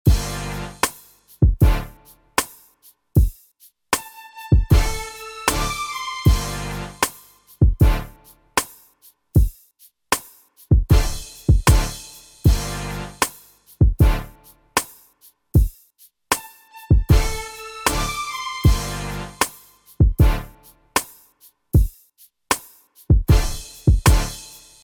West Coast Rap Beats